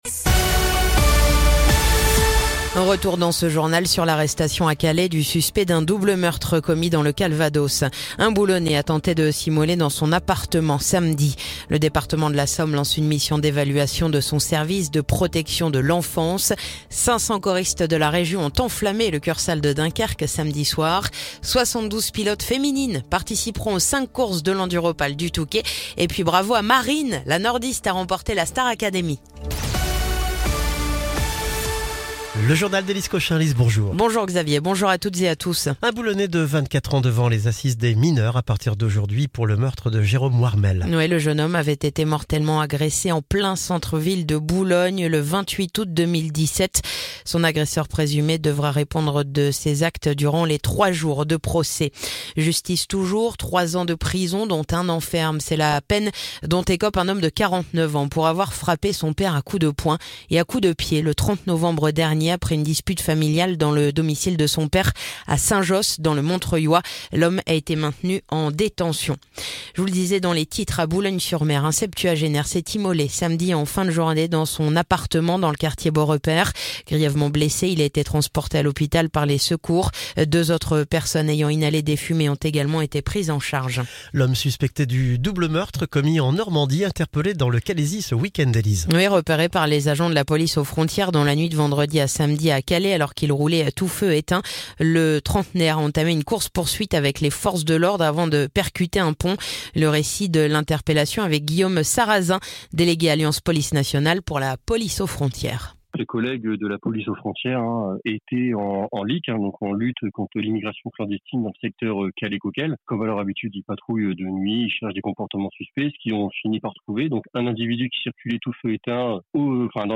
Le journal du lundi 27 janvier